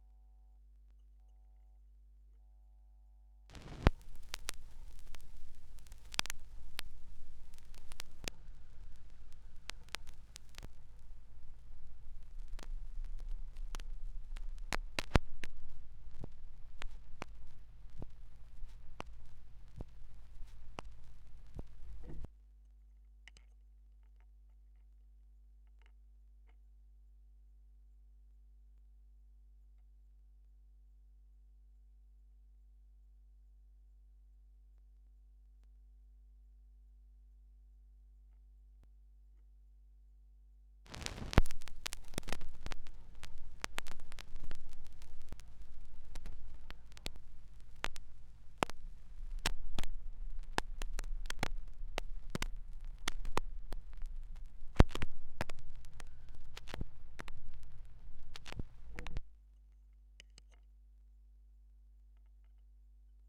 2017 Schallplattengeräusche (3).m3u